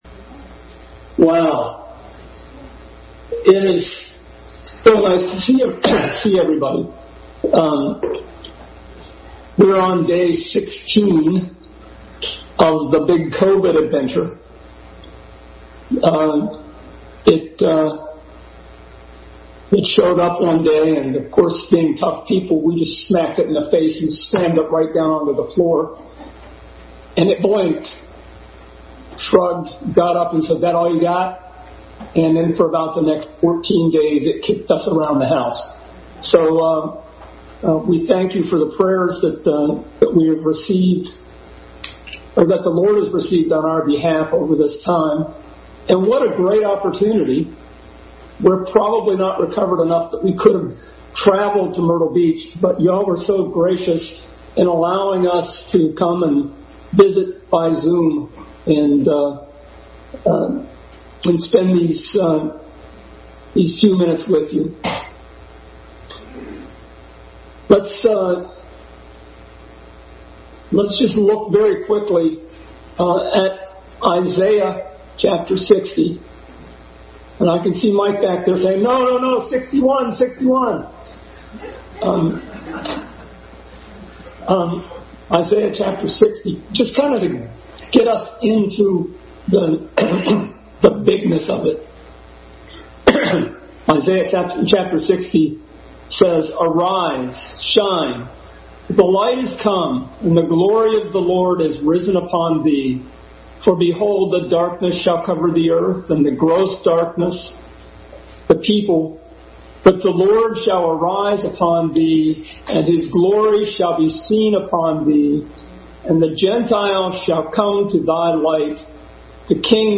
Adult Sunday School Class continued series of Christ in Isaiah.